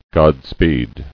[God·speed]